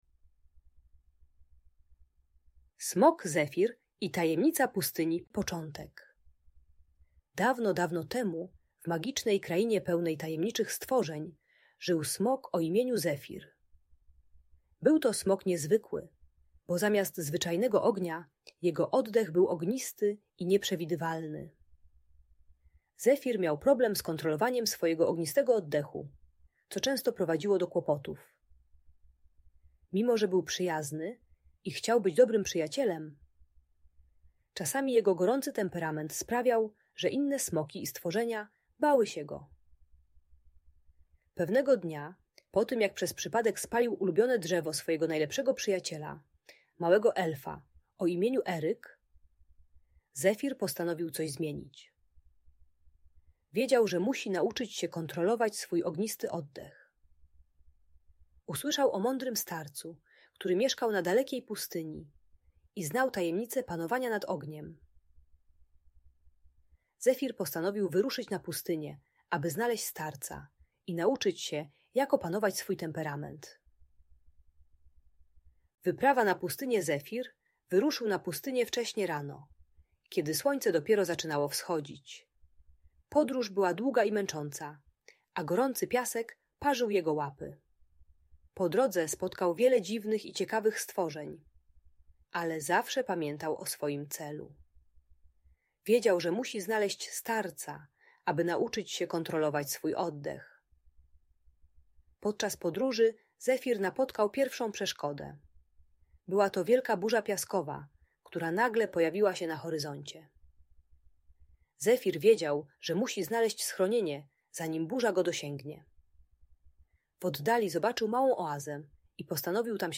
Smok Zefir i Tajemnica Pustyni - magiczna story - Audiobajka